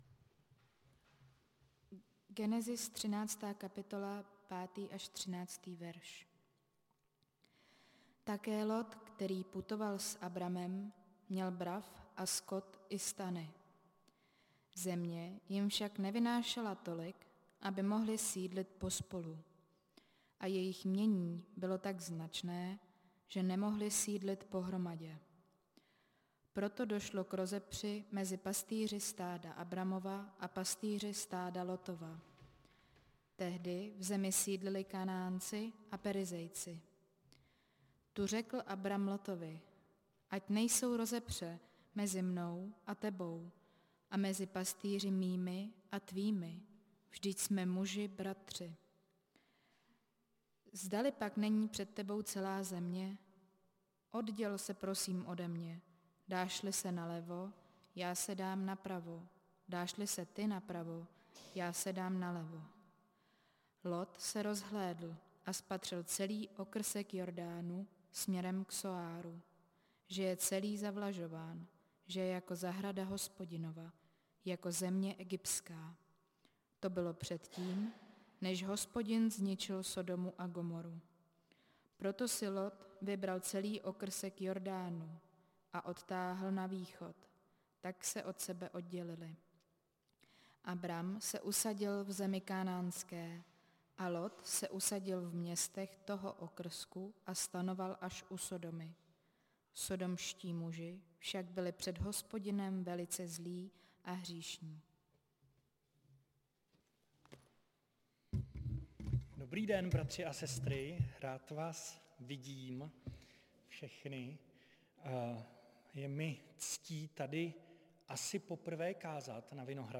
Nedělní kázání – 18.9.2022 Abram a Lot